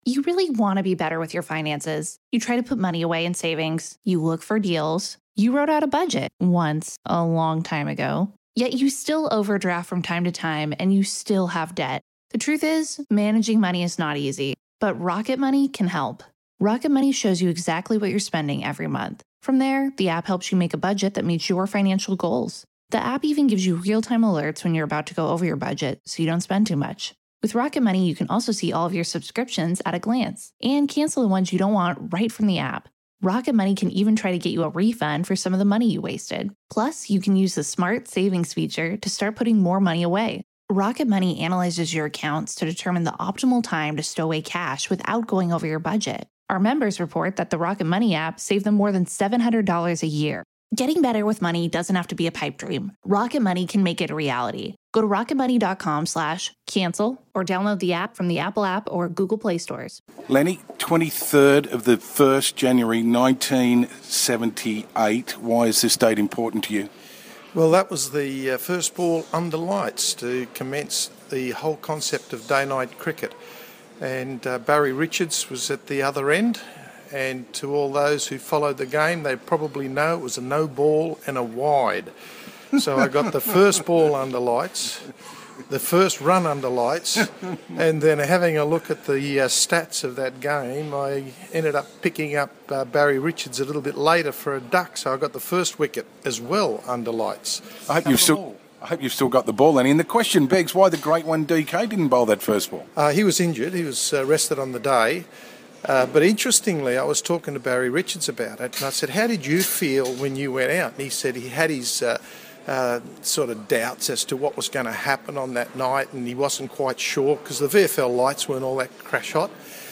Greg Matthews Interviews Len Pascoe Part 1